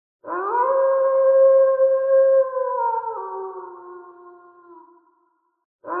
Wolf Howl
Wolf Howl is a free animals sound effect available for download in MP3 format.
042_wolf_howl.mp3